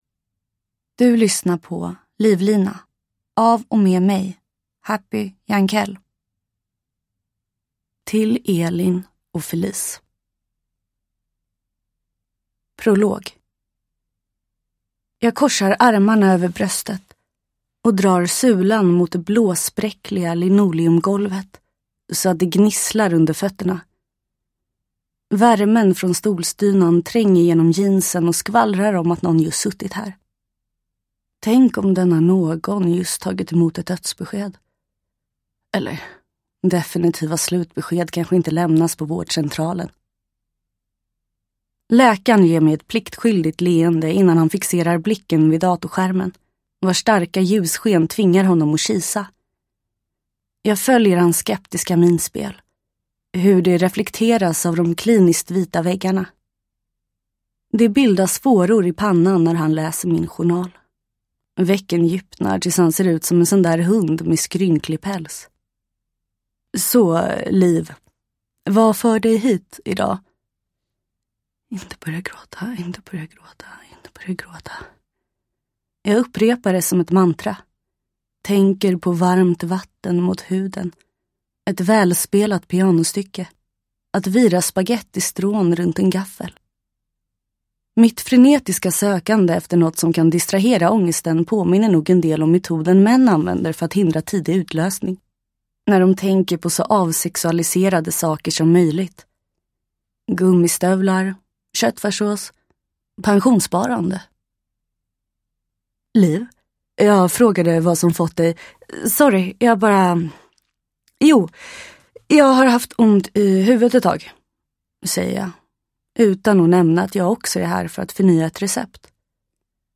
Uppläsare: Happy Jankell
Ljudbok